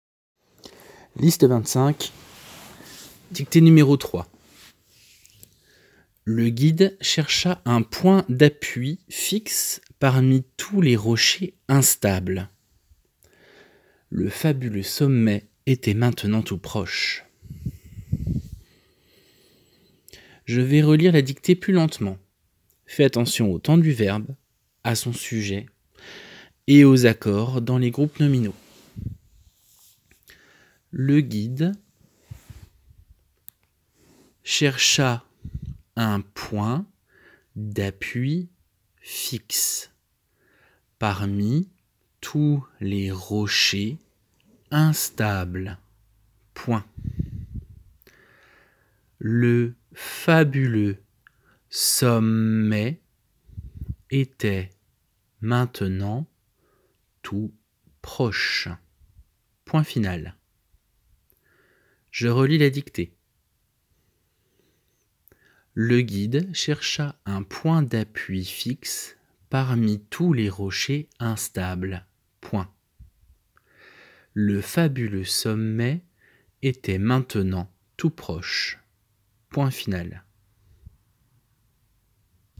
JDciZGXuS3e_Liste-25-dictée3.m4a